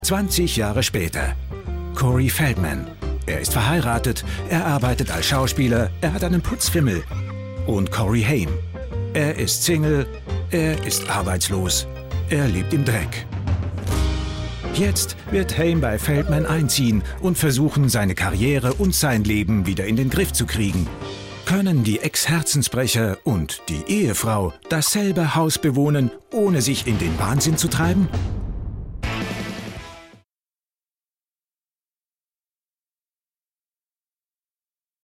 Profi Sprecher deutsch. Synchronsprecher, Werbesprecher. Sprecher für Hörspiele, Hörbücher, Imagefilm u.a.
Sprechprobe: Sonstiges (Muttersprache):